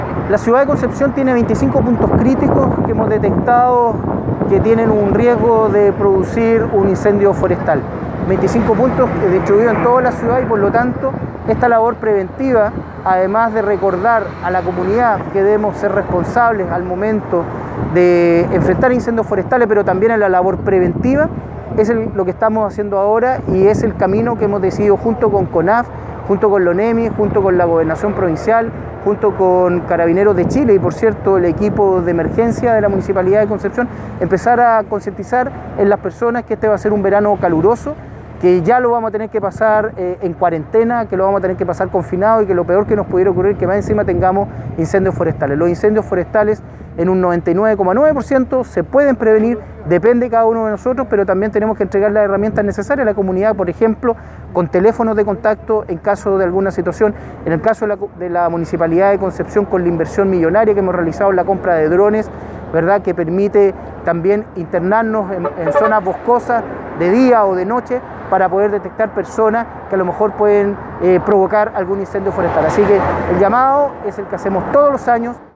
Por este motivo, a través de un operativo carretero en el sector Villa Concepción, organizado por la Corporación Nacional Forestal (CONAF) y la Municipalidad de Concepción, las autoridades locales insistieron en el llamado a la comunidad a redoblar sus esfuerzos en la prevención.
Asimismo, el alcalde de Concepción, Álvaro Ortiz, sostuvo que, en un verano caluroso y que transcurrirá principalmente en confinamiento producto del Coronavirus, lo peor que podría pasar sería enfrentar estas emergencias.